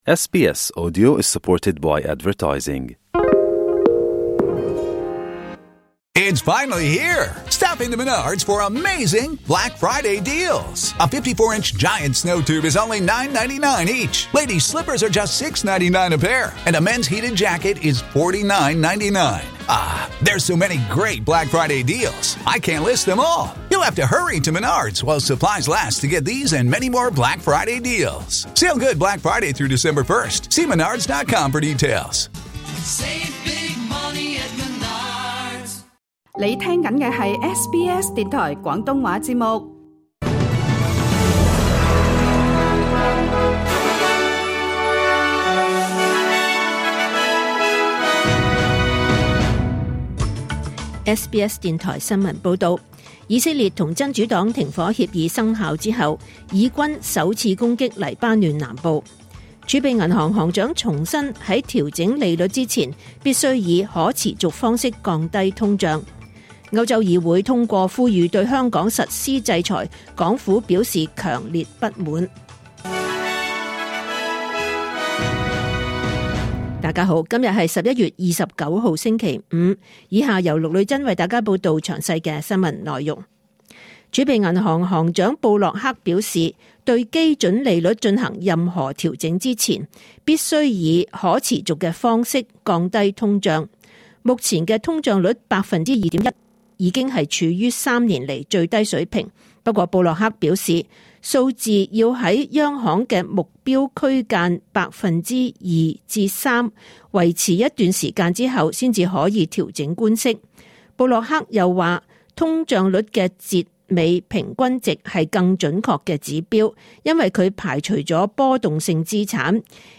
2024 年 11 月 29 日 SBS 廣東話節目詳盡早晨新聞報道。